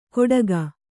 ♪ koḍaga